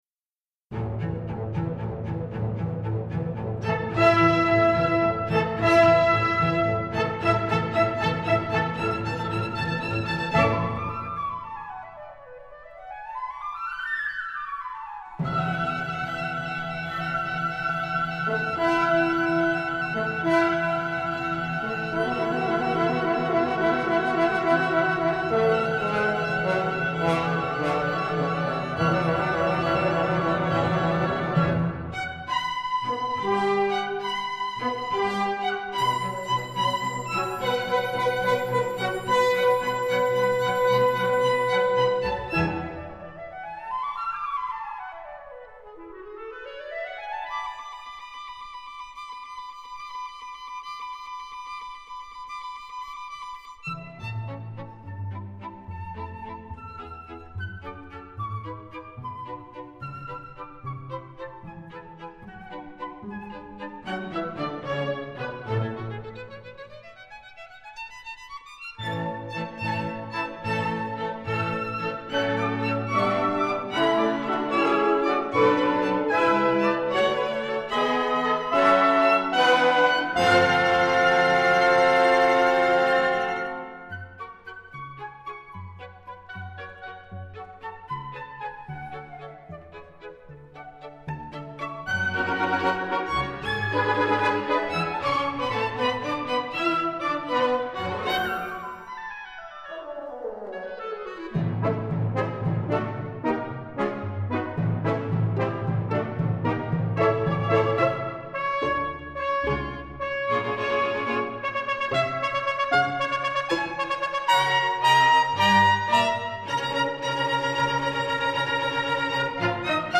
II Allegro giocoso